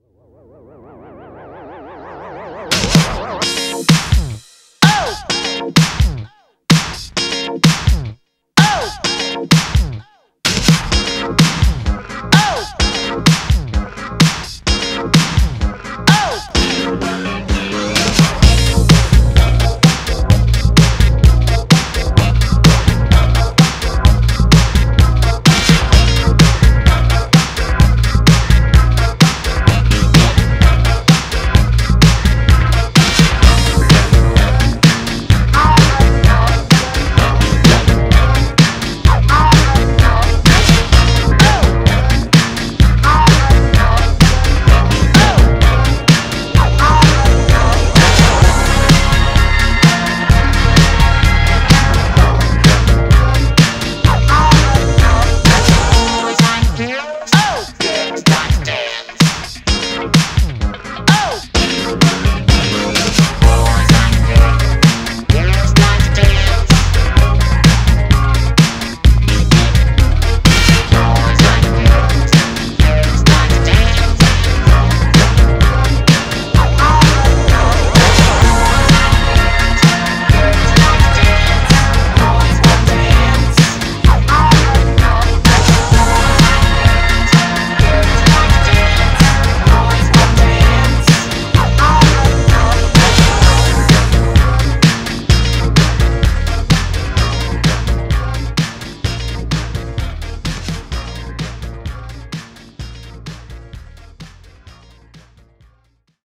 BPM128
Audio QualityPerfect (High Quality)